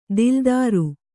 ♪ dildāru